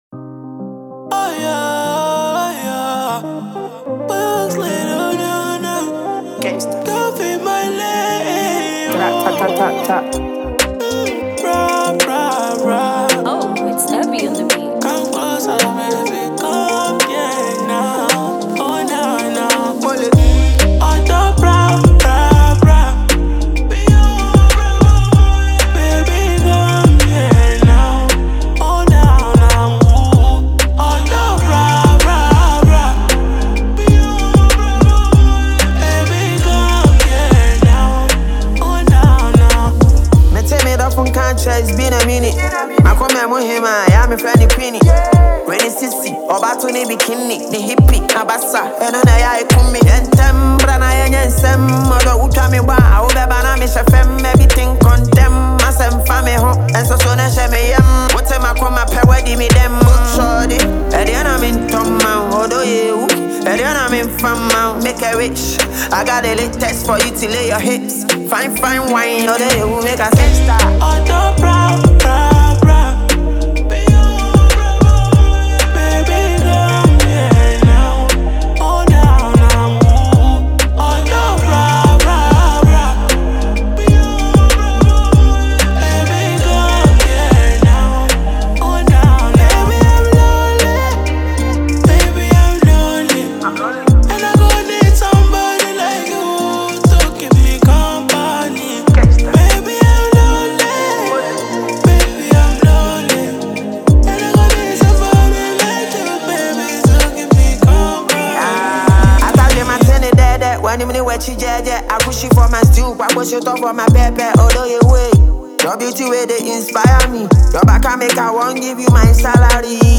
Ghanaian rapper and singer
Nigerian Afro-fusion star
a smooth and emotional love song
delivers sincere verses with a melodic touch